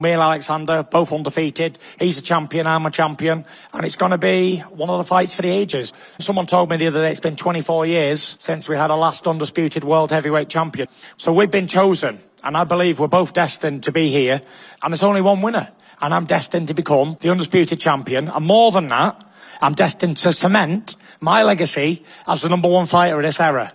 Fury says it will be a massive unification bout: